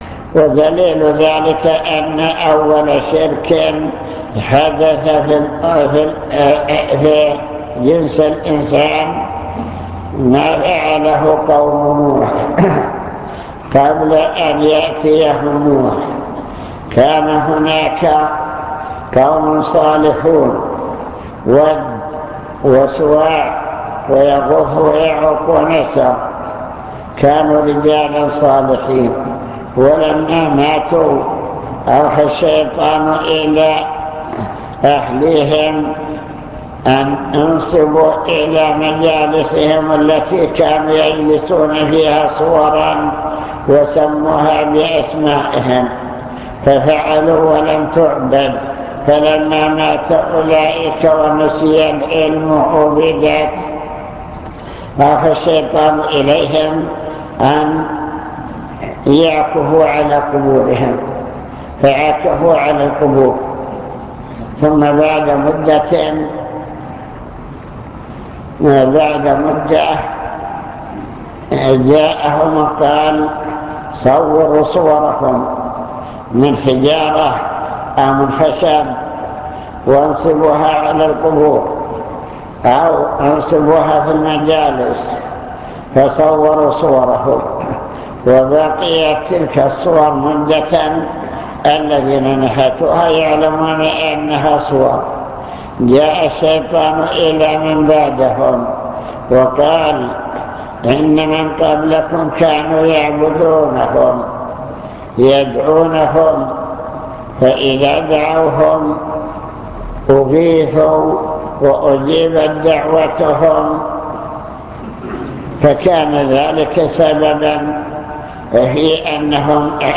المكتبة الصوتية  تسجيلات - محاضرات ودروس  محاضرة في أحكام التصوير